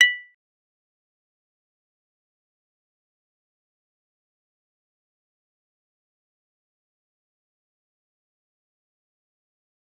G_Kalimba-B7-mf.wav